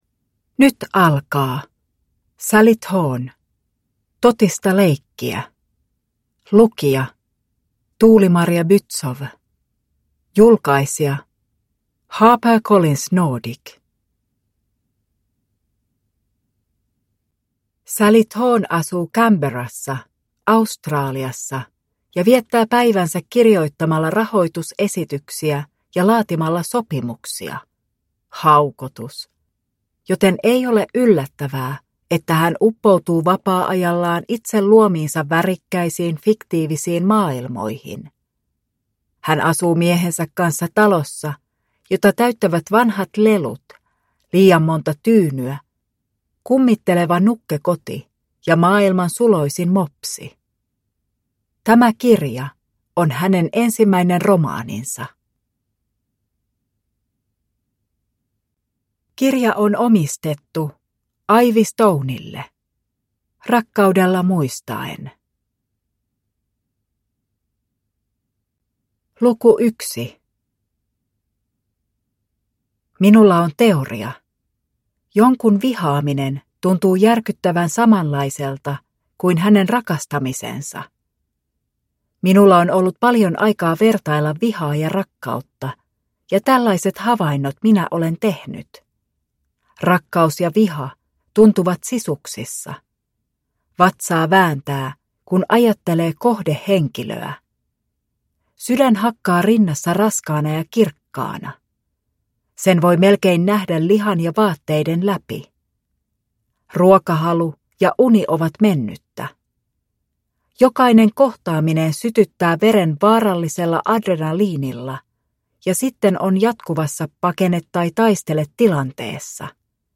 Totista leikkiä – Ljudbok – Laddas ner